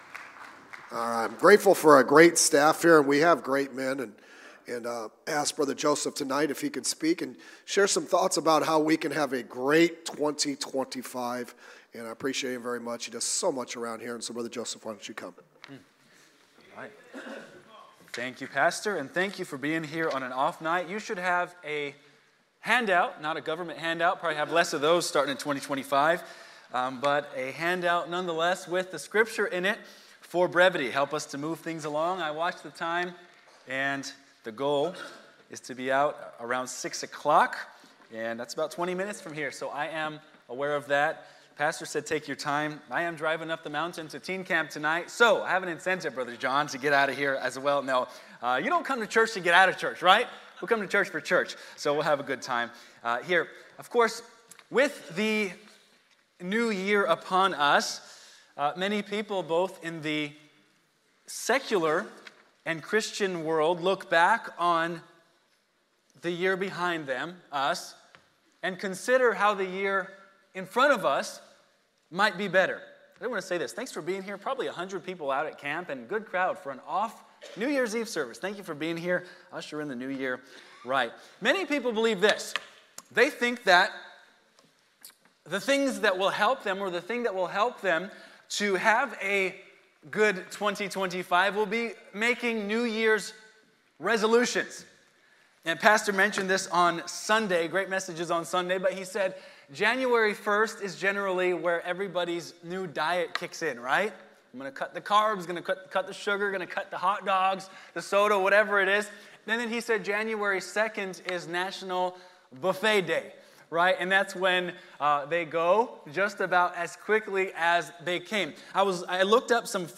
practical preaching